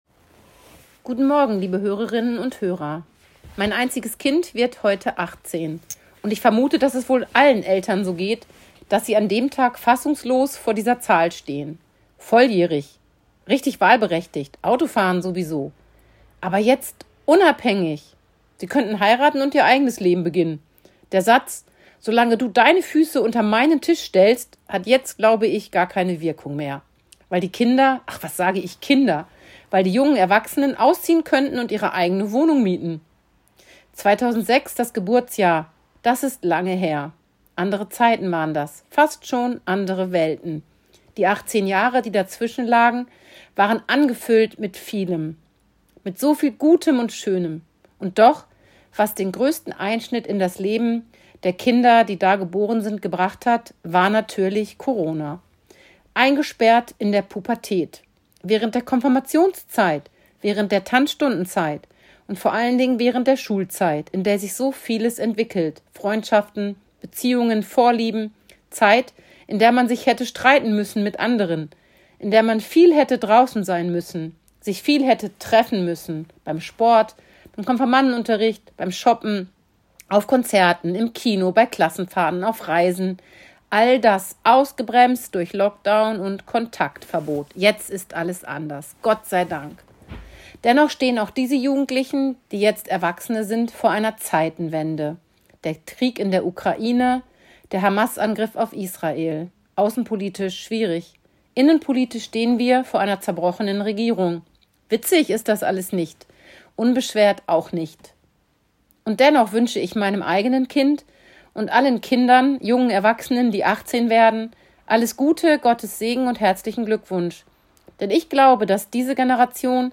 Radioandacht vom 15. November